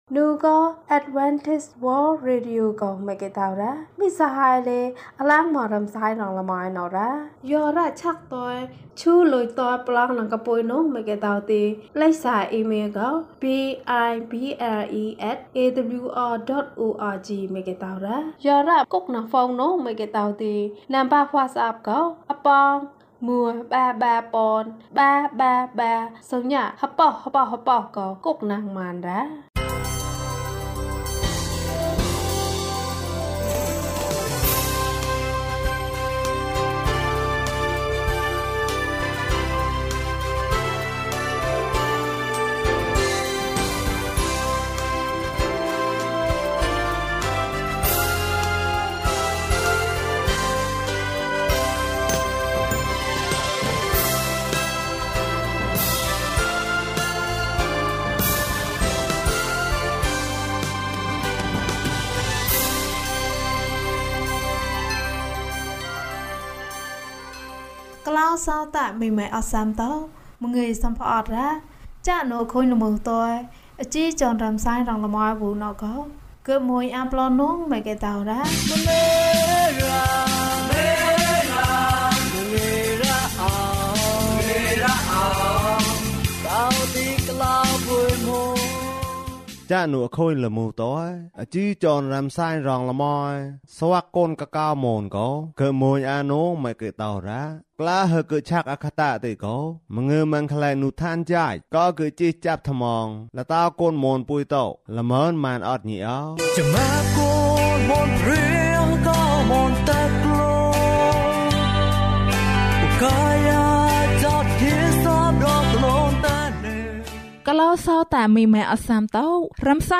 အချစ်ဆုံးသူငယ်ချင်း။ အပိုင်း ၂ ကျန်းမာခြင်းအကြောင်းအရာ။ ဓမ္မသီချင်း။ တရားဒေသနာ။